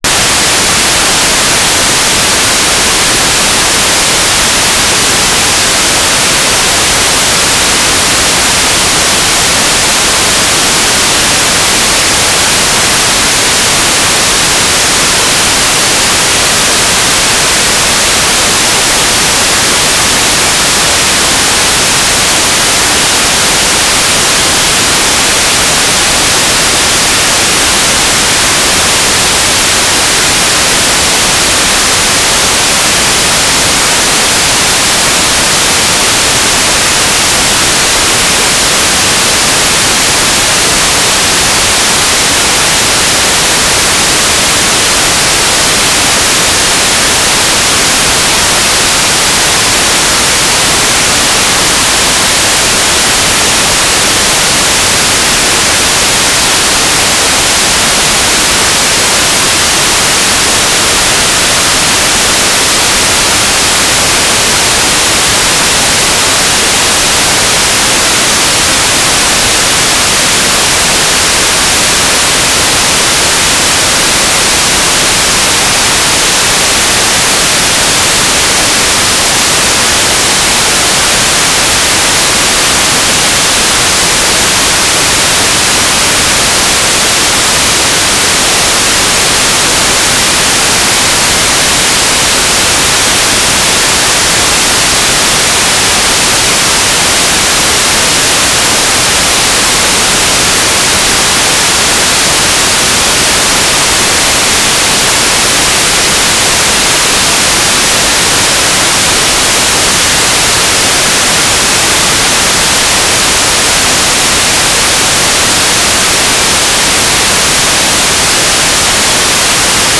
"transmitter_description": "Mode U - GMSK 9k6 (USP) TLM",
"transmitter_mode": "GMSK USP",